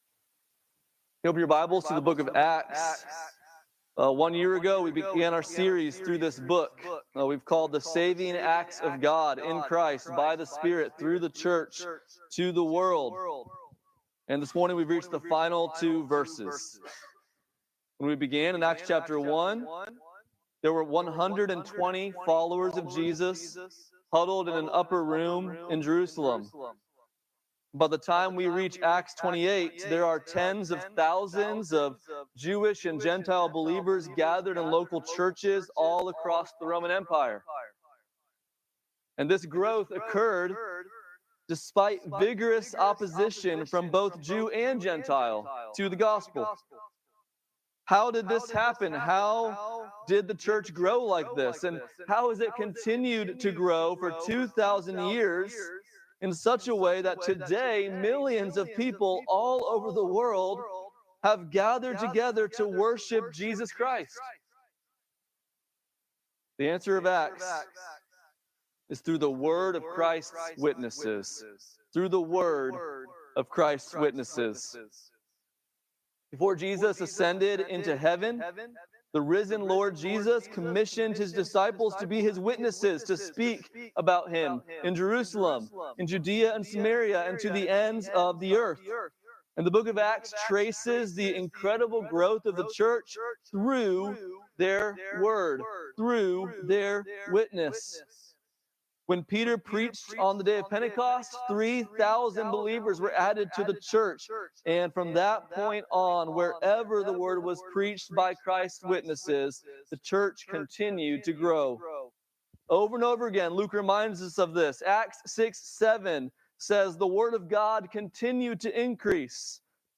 Acts 28:30-31 Service Type: Sunday Morning « Good Friday 2025 In the Beginning
Easter-Sunday-at-Redeemer.mp3